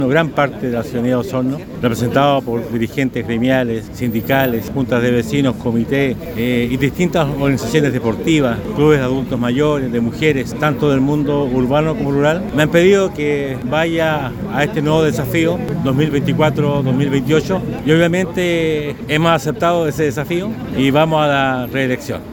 Carrillo, en conversación con Radio Sago, agradeció el apoyo de su partido y también de diversos sectores de la comunidad osornina, para intentar continuar al mando de la municipalidad.